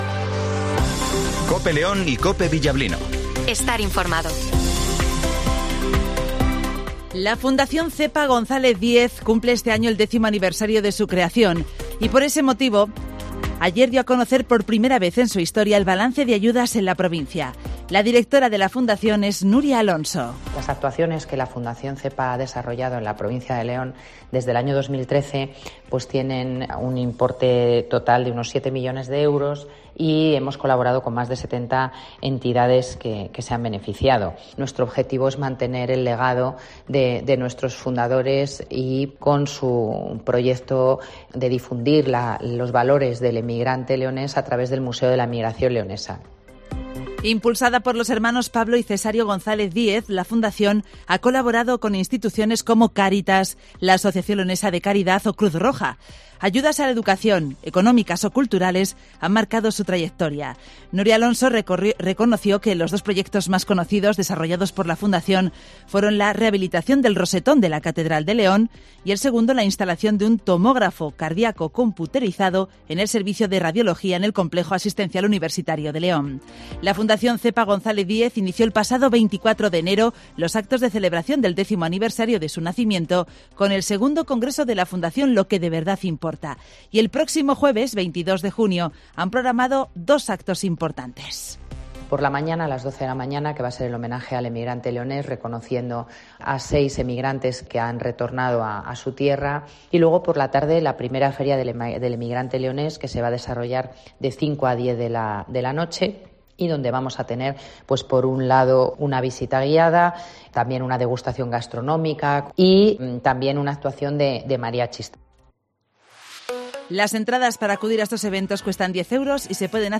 Redacción digital Madrid - Publicado el 16 jun 2023, 08:20 - Actualizado 16 jun 2023, 13:09 1 min lectura Descargar Facebook Twitter Whatsapp Telegram Enviar por email Copiar enlace - Informativo Matinal 08:20 h